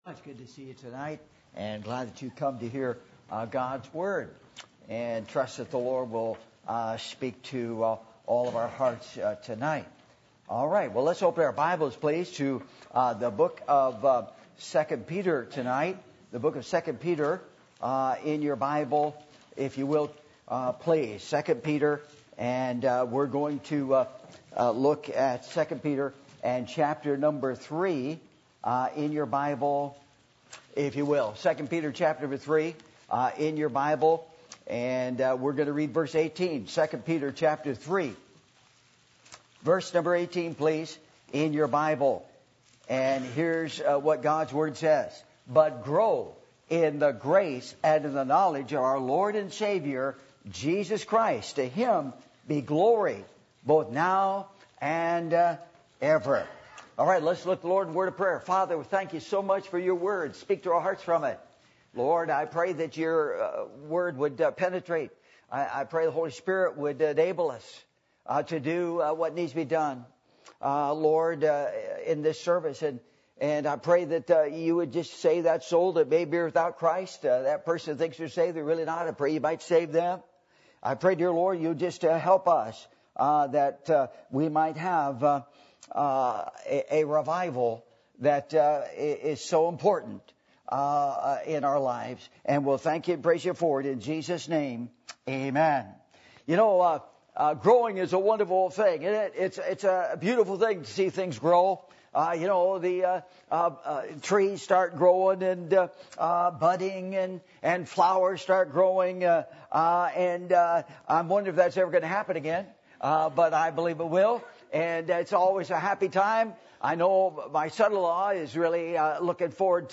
2 Peter 3:18-19 Service Type: Revival Meetings %todo_render% « You Must Be Born Again!